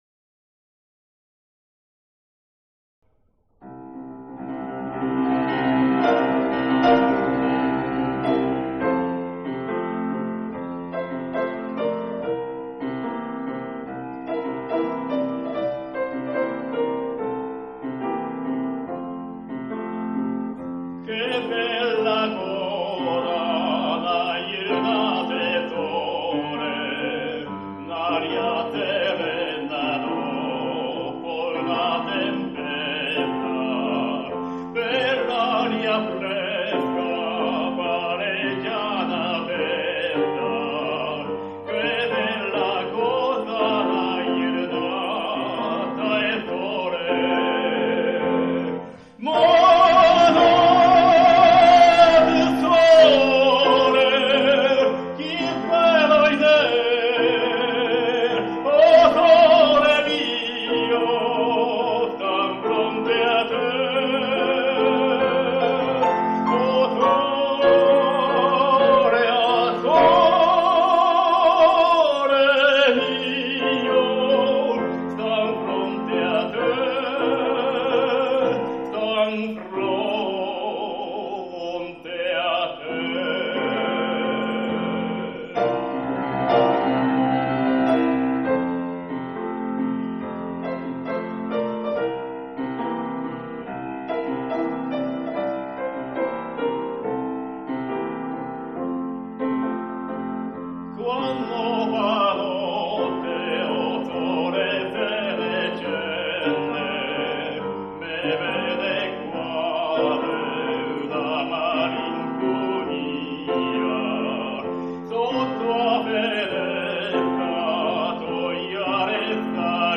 Ｏ sole mio ！（ボサノバ）